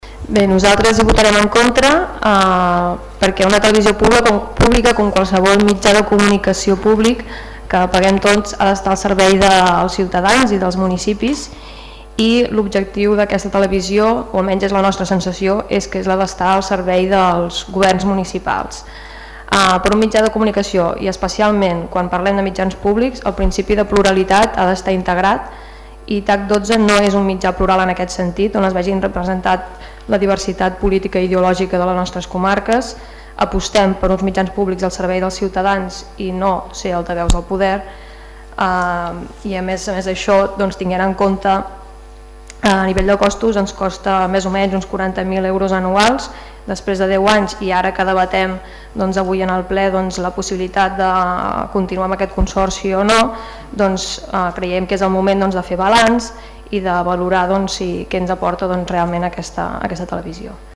El ple de l’Ajuntament de Montblanc d’aquest dimecres va aprovar renovar el conveni amb TAC12, la televisió pública del Camp de Tarragona.